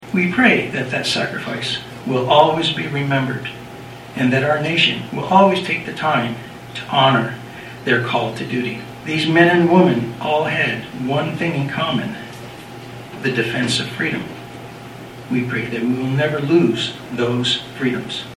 The post’s ceremony began with a prayer
The ceremony, which was moved indoors due to rain Monday, honored all the fallen soldiers from all of the wars throughout this country’s history.